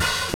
Hat (80).wav